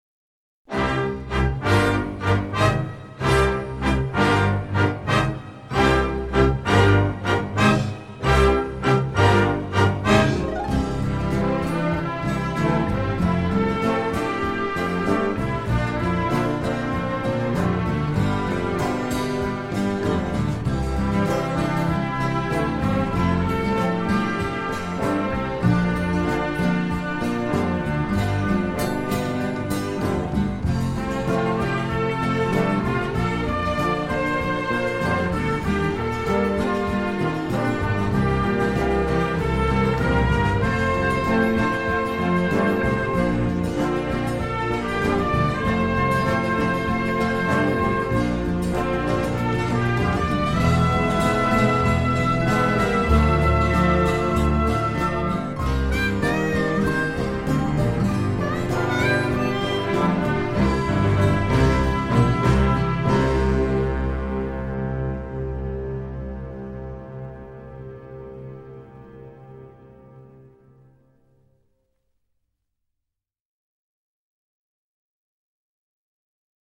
sympathique score de western